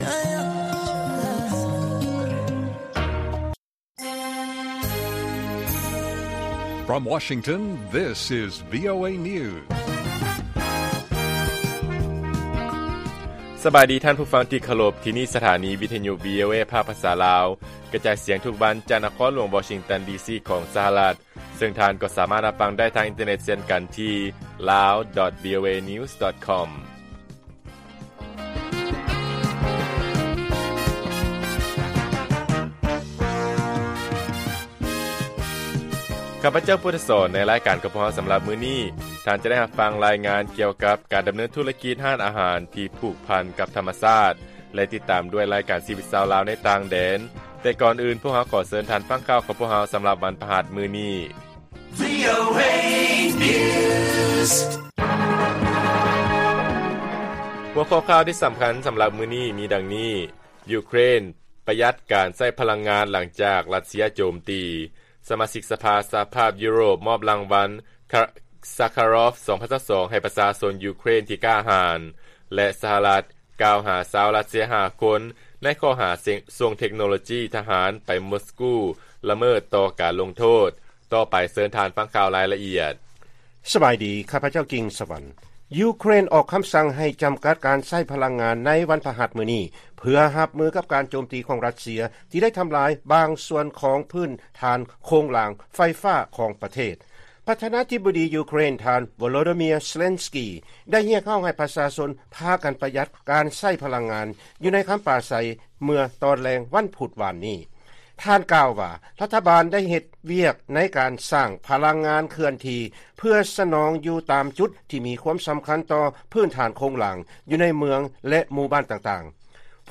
ລາຍການກະຈາຍສຽງຂອງວີໂອເອ ລາວ: ຢູເຄຣນ ປະຍັດການໃຊ້ພະລັງງານ ຫລັງຈາກຣັດເຊຍໂຈມຕີ